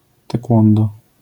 (spolszczona) ‹tekłondo›, IPA[tɛˈkwɔ̃ndɔ], AS[teku̯õndo], zjawiska fonetyczne: nazal.